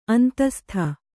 ♪ antastha